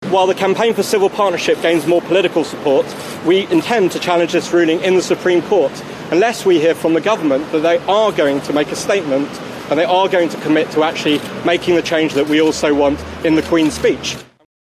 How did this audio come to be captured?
Speaking outside the Court of Appeal